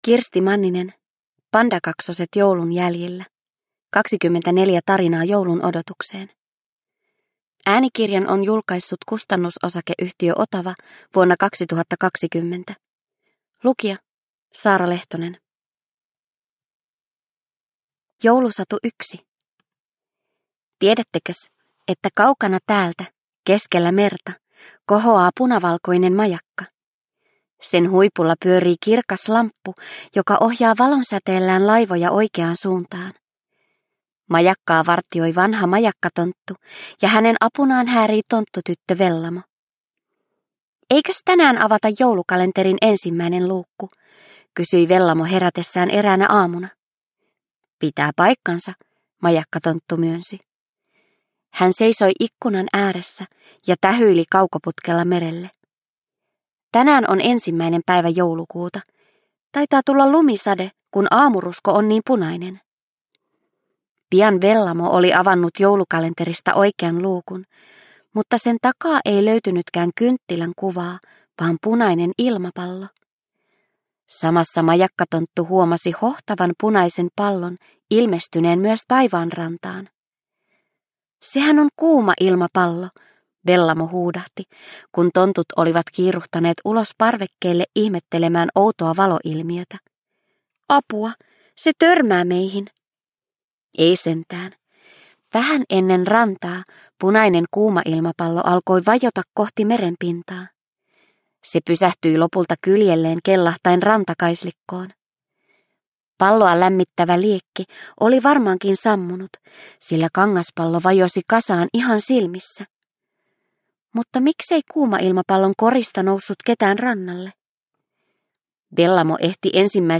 Pandakaksoset joulun jäljillä – Ljudbok – Laddas ner